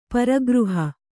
♪ para gřha